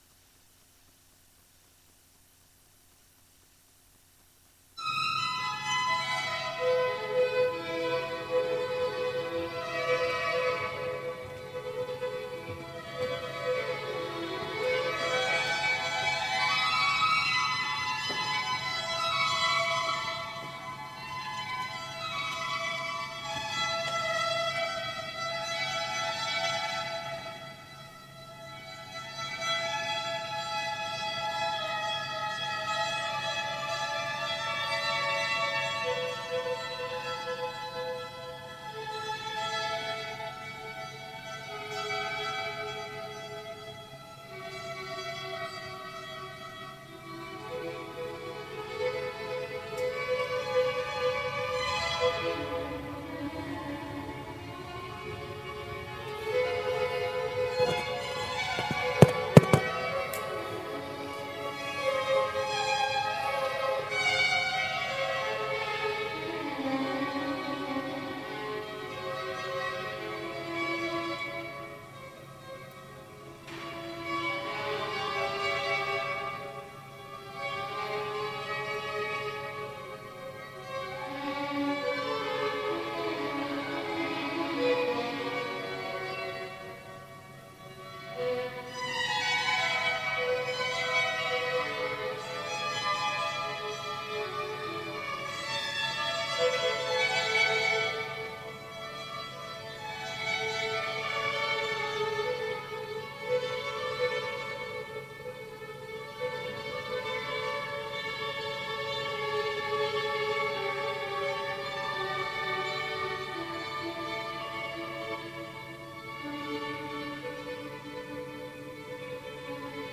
Écouter le culte entier (Télécharger au format MP3)